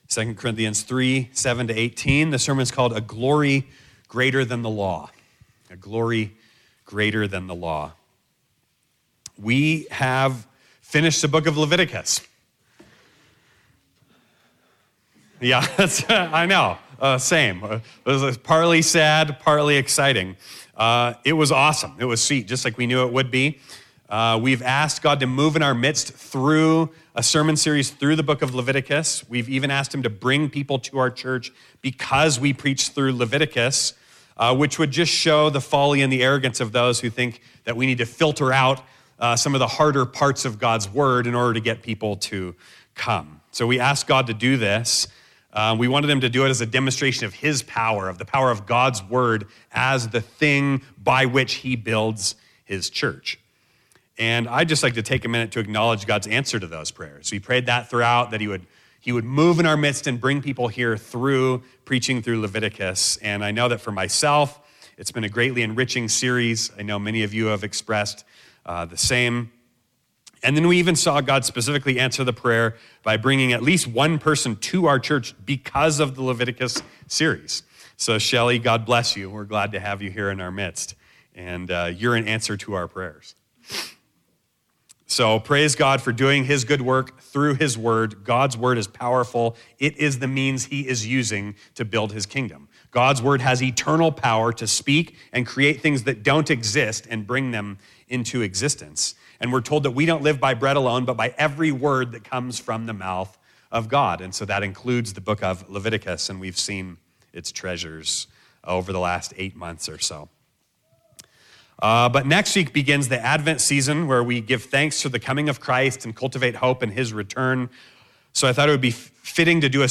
Sermons Gospel Church Durango พอดคาสต์